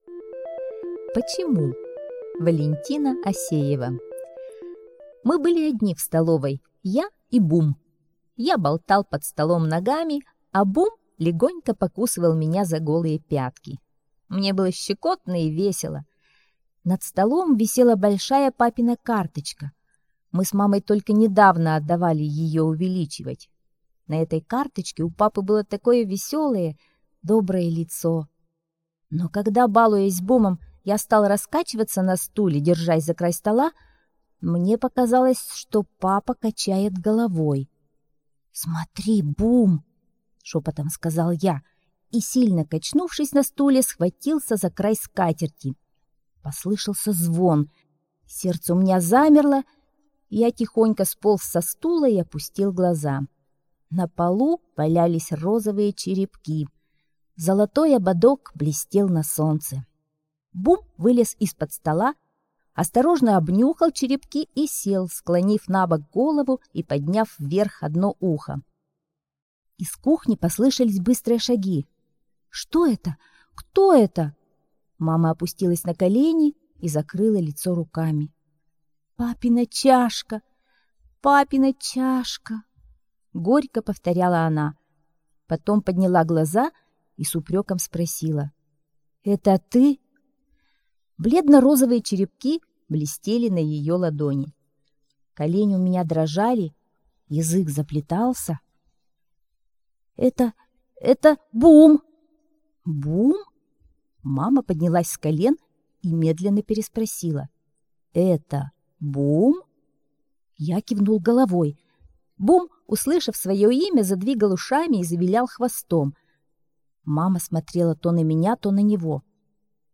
Аудиокнига в разделах